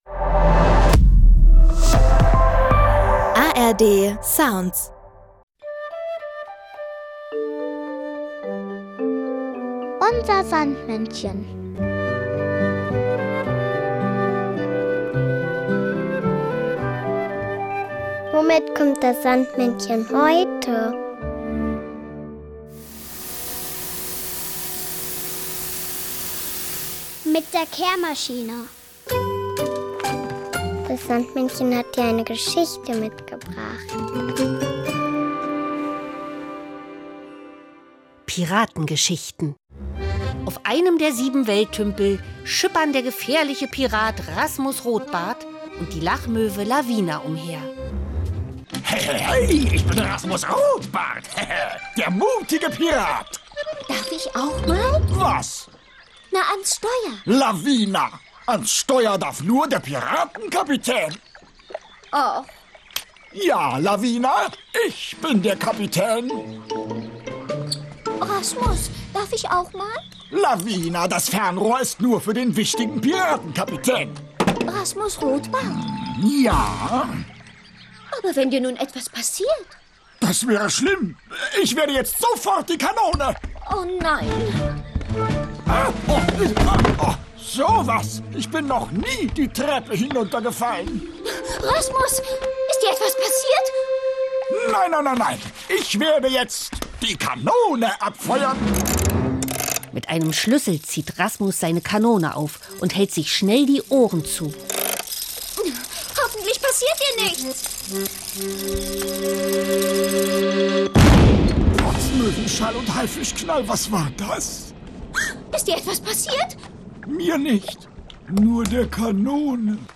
noch das Kinderlied "Fisch" von The Düsseldorf Düsterboys.